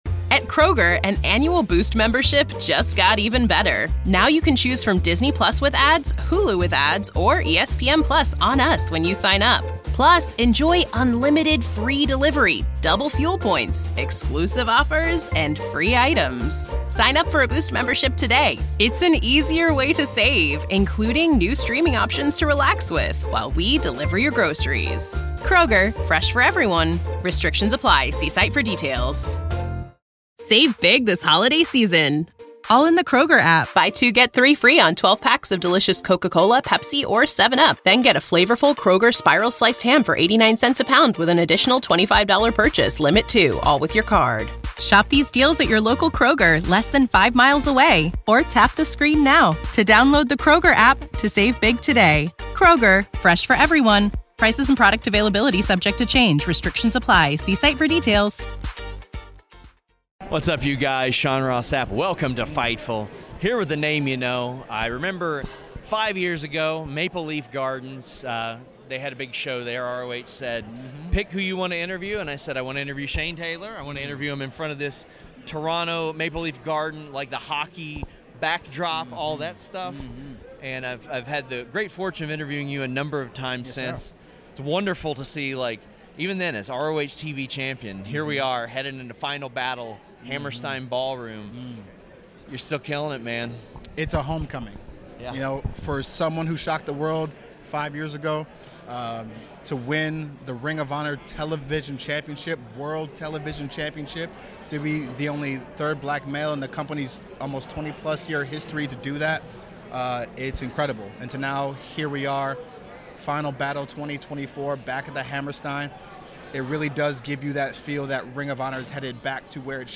Interview | Fightful News